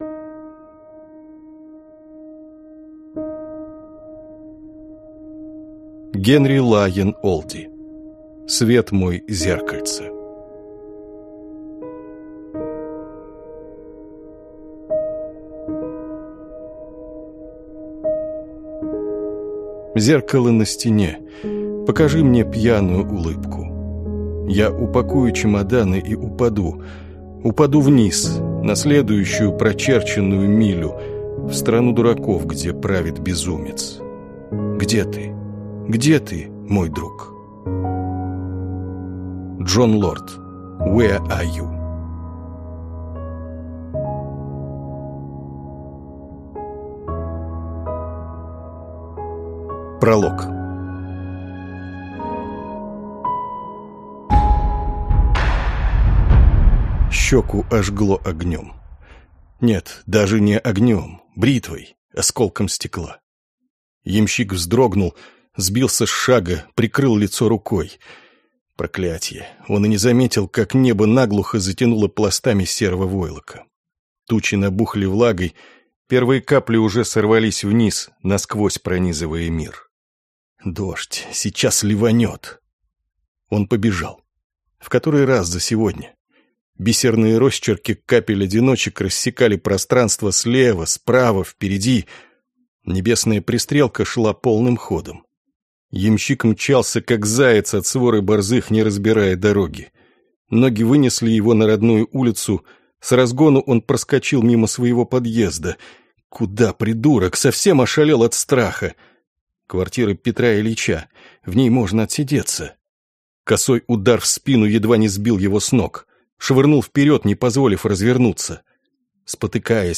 Аудиокнига Свет мой, зеркальце…